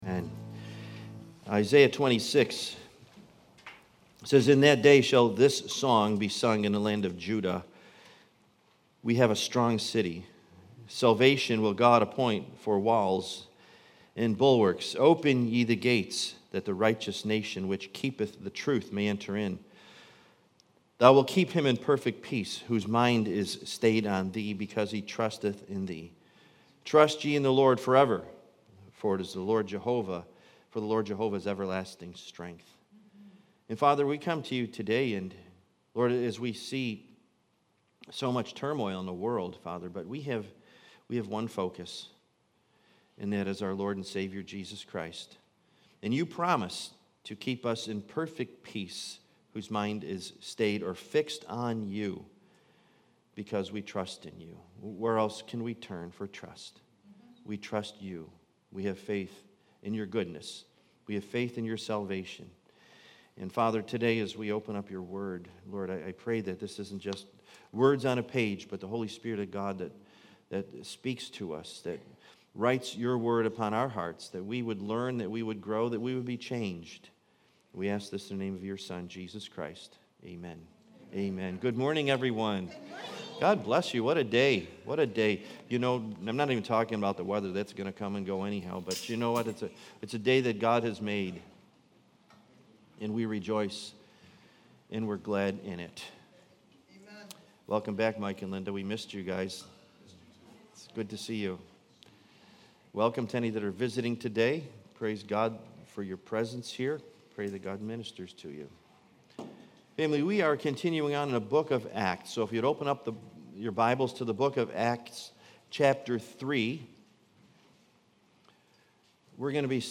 Series: Sunday Morning